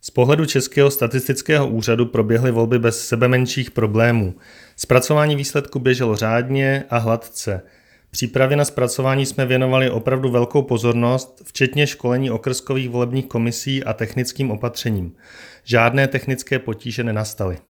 Vyjádření místopředsedkyně ČSÚ Evy Krumpové, soubor ve formátu MP3, 719.43 kB